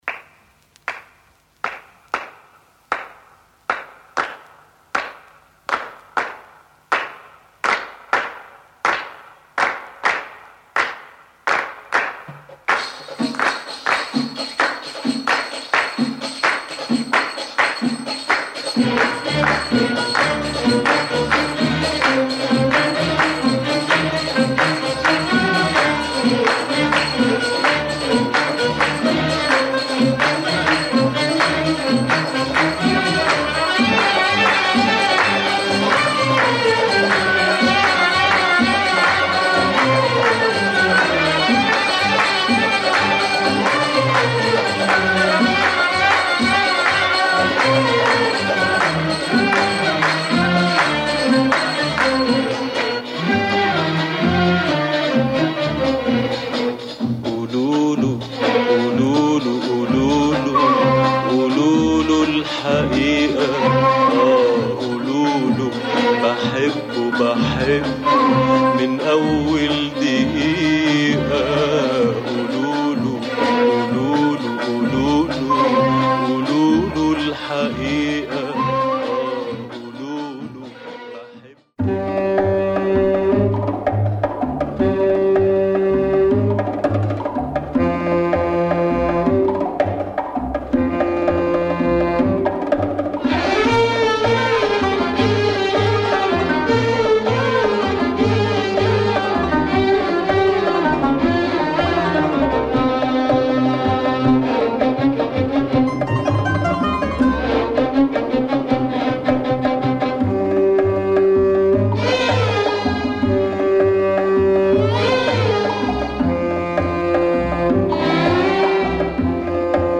Light surface marks, still ok.
LP